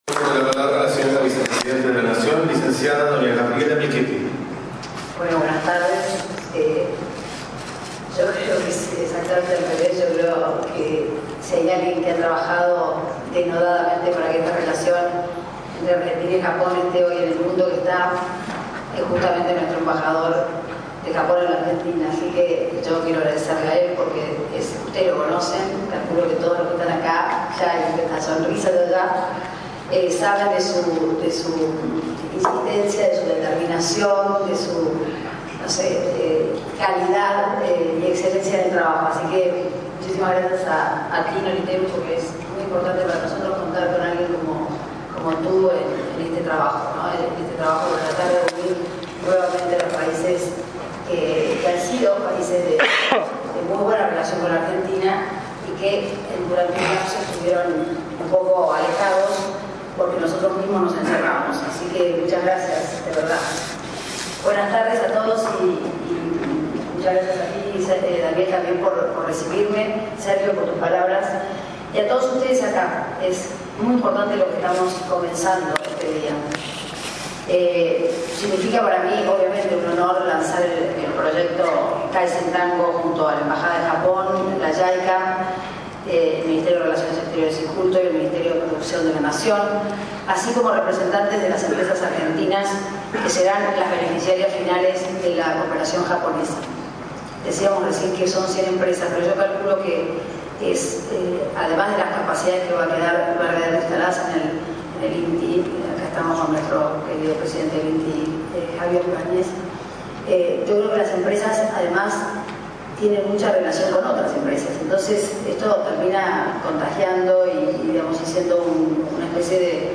En las bellísimas  instalaciones del Palacio San Martín conservadas y cuidadas como en sus orígenes  el miércoles 25  de octubre se lanzó oficialmente el proyecto “Red de Asistencia Técnica para Oportunidades Globales de Kaizen”, denominado  “Kaizen-Tango”
Audio:  Vicepresidente de la Nación, Gabriela Michetti